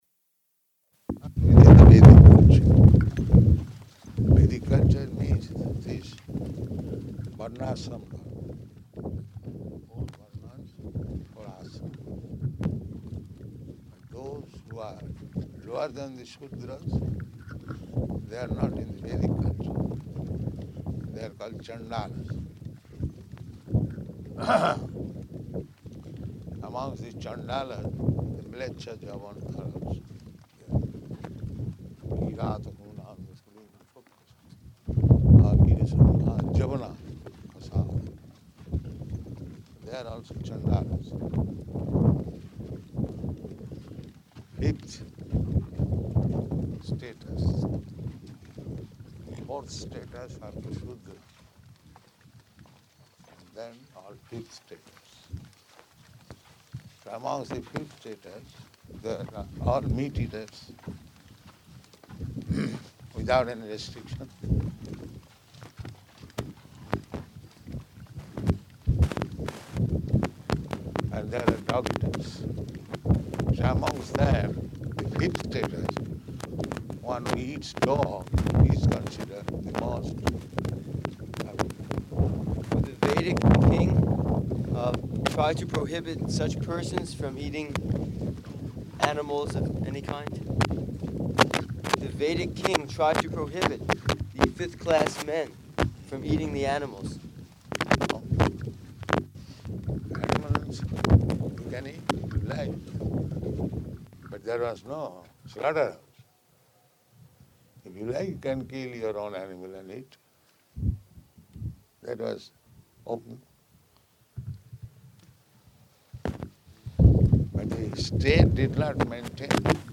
Morning Walk --:-- --:-- Type: Walk Dated: April 6th 1975 Location: Māyāpur Audio file: 750406MW.MAY.mp3 Prabhupāda: ...not within the Vedic culture.